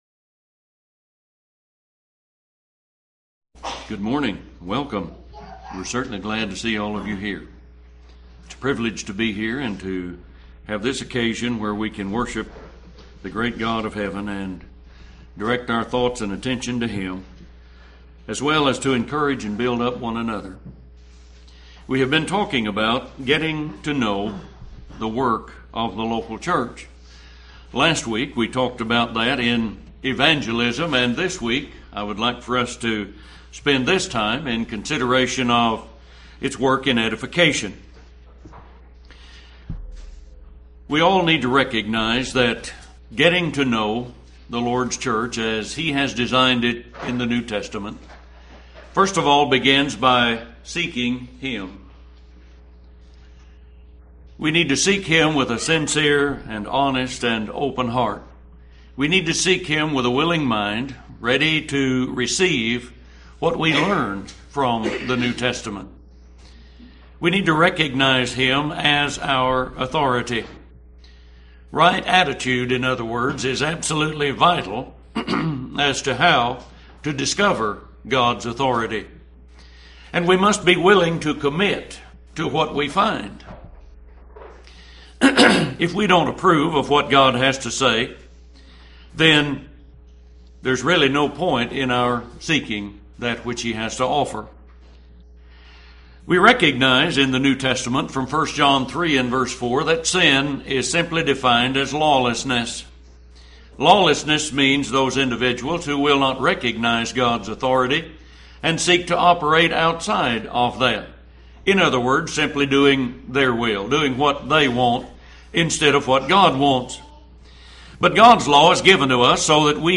Getting to Know the Church #1 Sermon Title Speaker Date Time What is the Chruch?